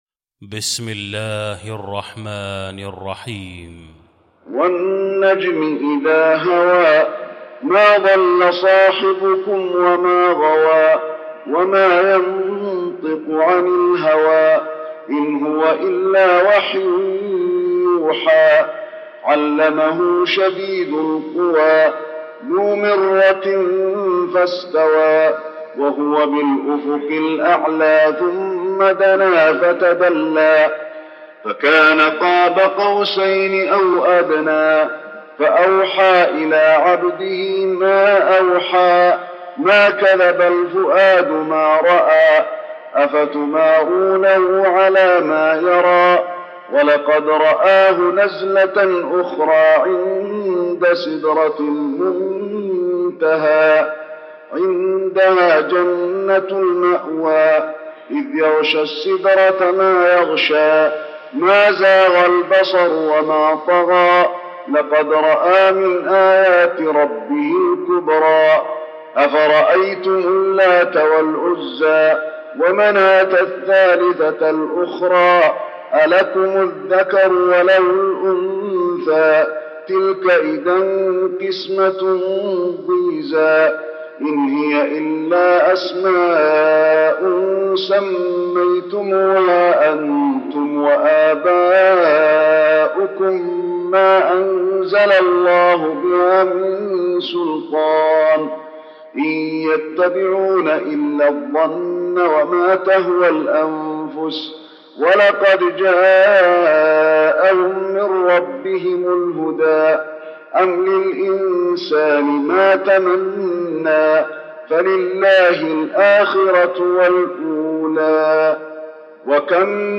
المكان: المسجد النبوي النجم